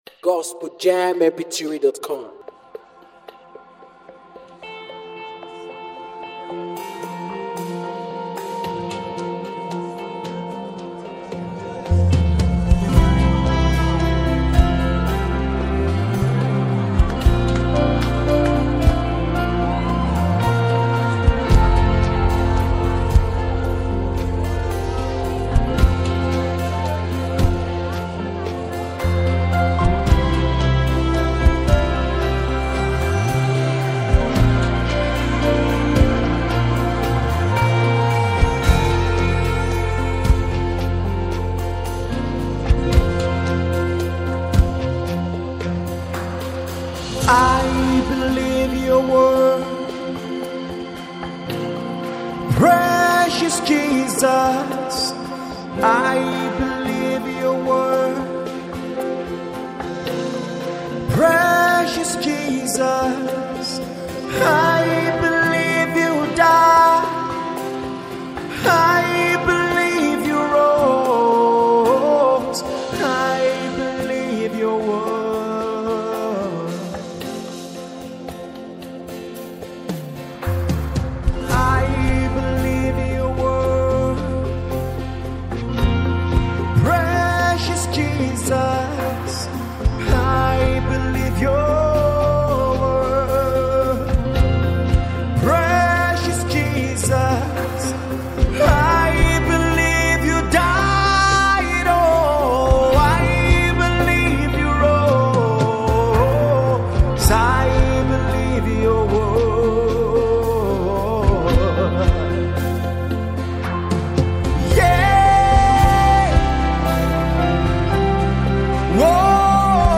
a powerful mind blowing worship.